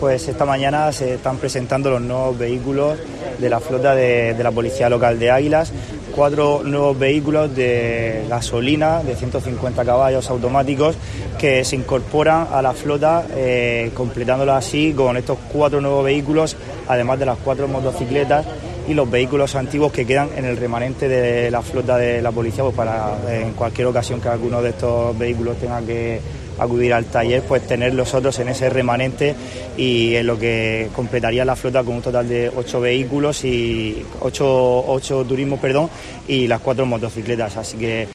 Cristóbal Casado, concejal Seguridad Ciudadana de Águilas